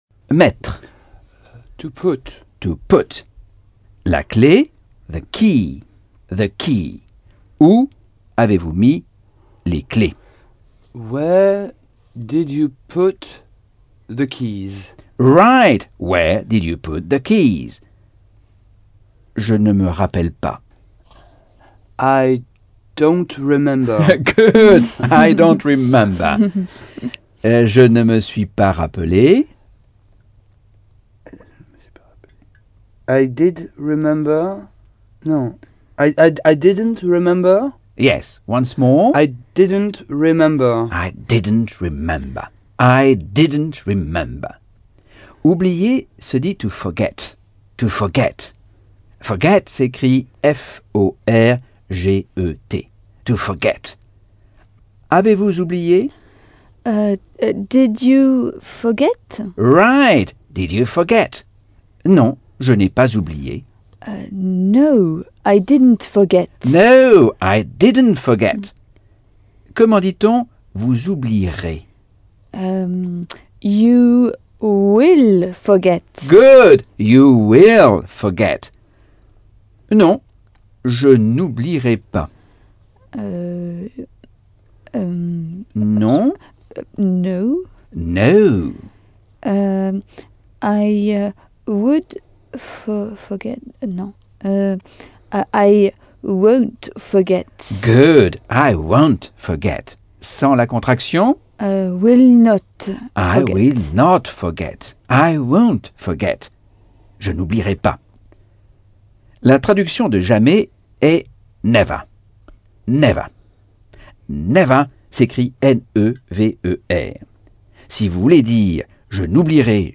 Leçon 3 - Cours audio Anglais par Michel Thomas - Chapitre 6